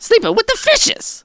crow_kills_02.wav